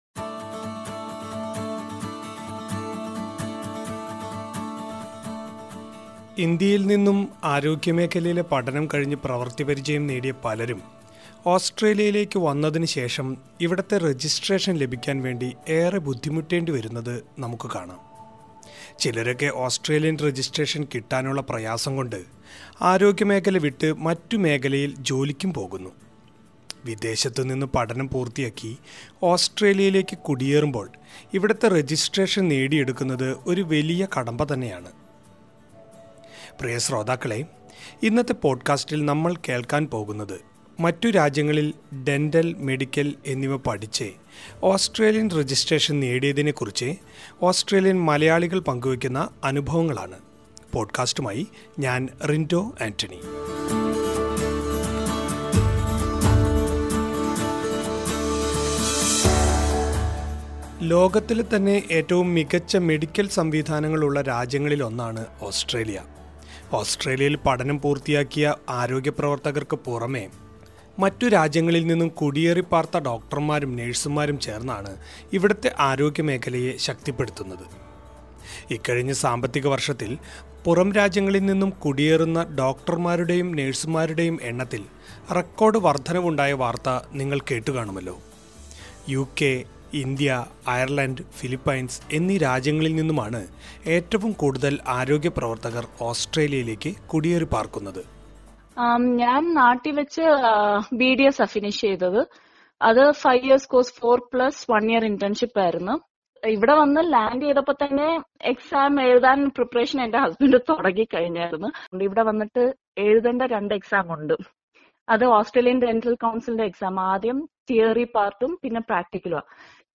എന്നാല്‍, ഇന്ത്യ പോലുള്ള രാജ്യങ്ങളില്‍ നിന്ന് മെഡിക്കല്‍ പഠനം പൂര്‍ത്തിയാക്കുന്നവര്‍ക്ക് അവരുടെ യോഗ്യതകള്‍ക്ക് ഓസ്‌ട്രേലിയയില്‍ അംഗീകാരം നേടുന്നത് ഇപ്പോഴും എളുപ്പമല്ല എന്നാണ് വ്യാപകമായി ഉയരുന്ന പരാതി. ഓസ്‌ട്രേലിയയിലേക്ക് കുടിയേറിയ രണ്ടു മലയാളി ഡോക്ടര്‍മാരുടെ അനുഭവങ്ങള്‍ കേള്‍ക്കാം..